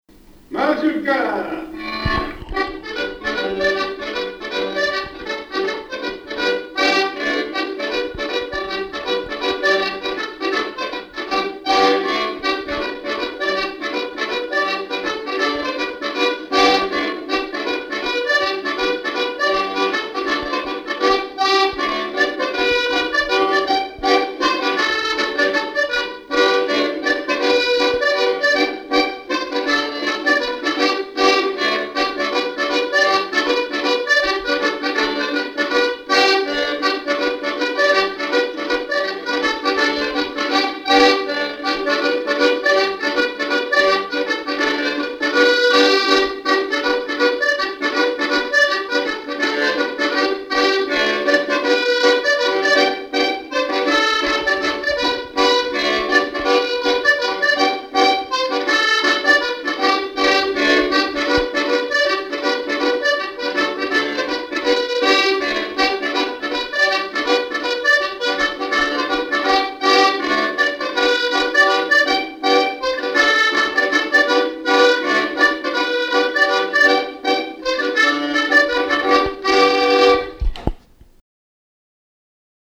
Mazurka
danse : mazurka
Pièce musicale inédite